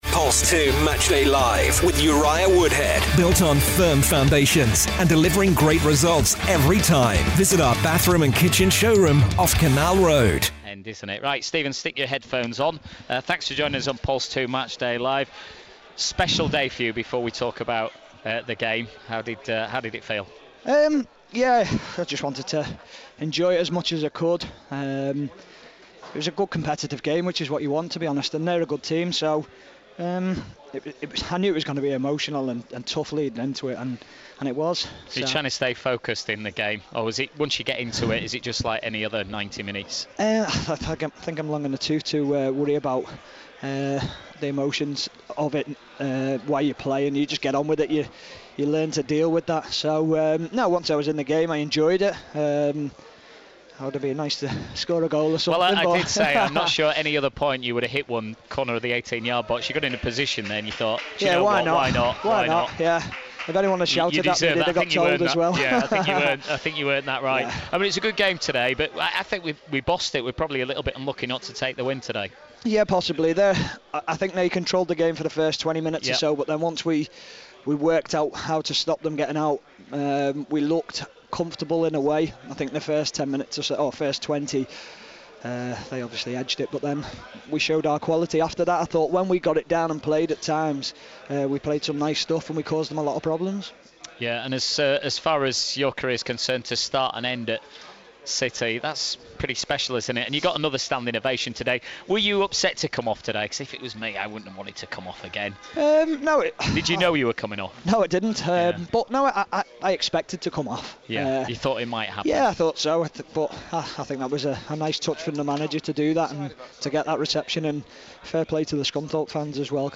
Interviews: Stephen Warnock & Simon Grayson